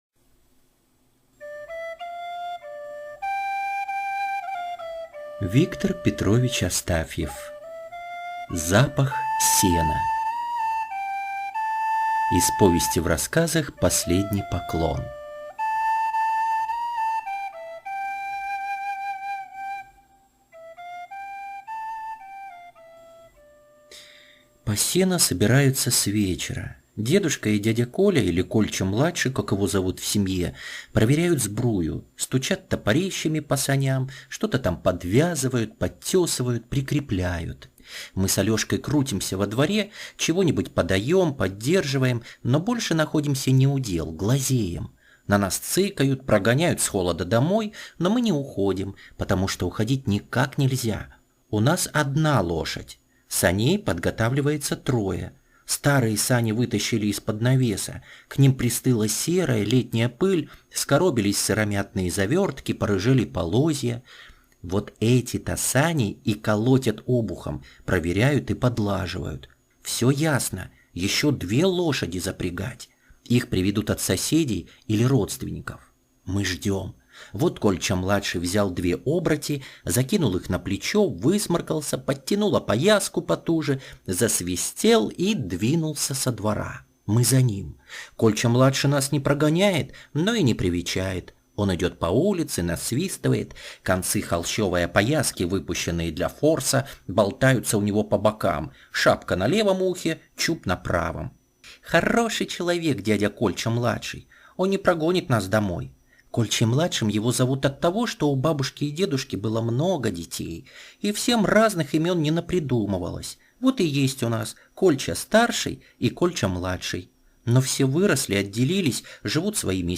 Запах сена - аудио рассказ Астафьева В.П. Двое мальчишек, двоюродные братья, рвутся помочь дедушке и дяде привезти сено с дальних покосов...